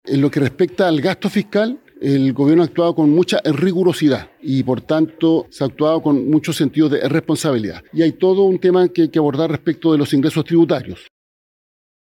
El ministro del Interior, Álvaro Elizalde, defendió la gestión del Ejecutivo en materia tributaria.